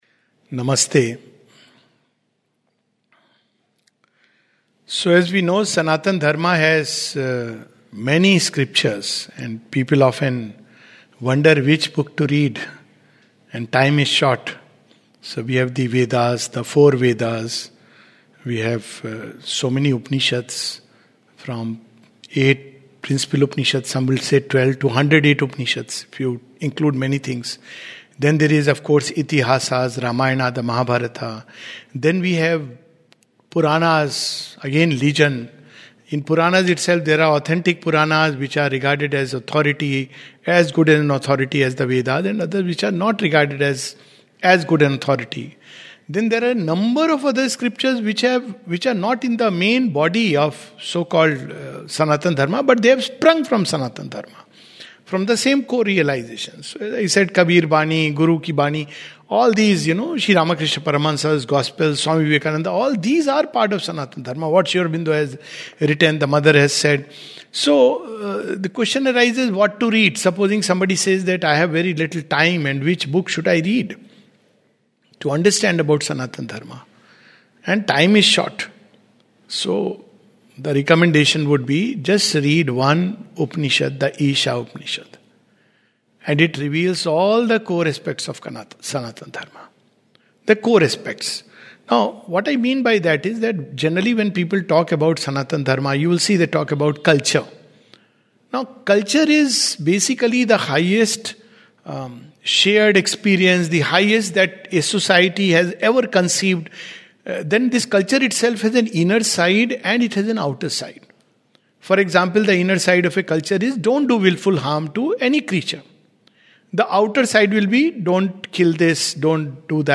In this talk we touch upon some of the core aspects of Sanatana Dharma especially its idea of the Divine and its practical implications.